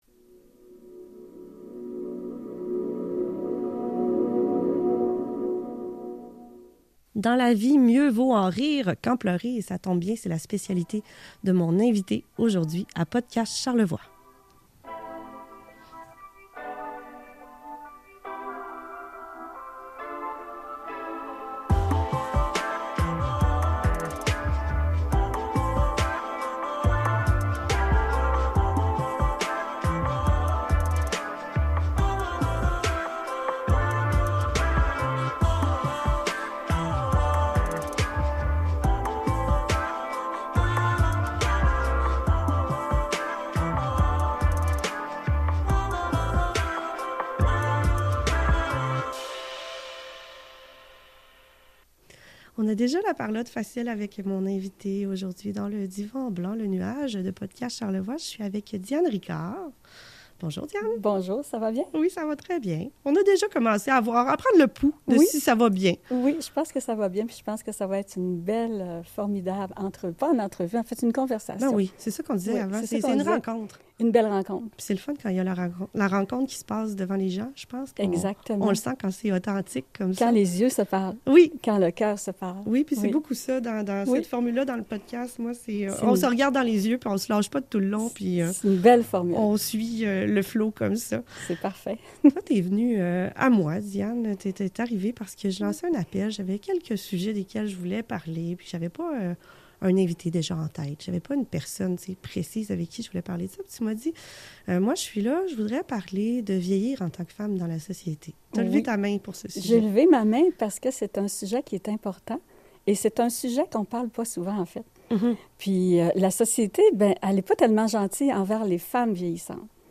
Une jasette à coeur ouvert qui évolue entre le yoga du rire, la boulimie littéraire, devenir clown ou femme statue, vivre simplement, marcher compostelle à cinquante ans, sortir de la boite et surtout envisager la dernière ligne droite de la vie avec l'envie de s'amuser et d'être dans le moment présent!